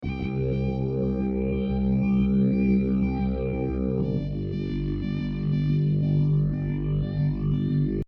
声乐 " 啊，女声 放松，快乐
描述：女声在录音室里说"Ahh"，有各种语气的快乐、和平、愉快
标签： 性感 快乐 轻松 语音 酒足饭饱之后 愉快
声道立体声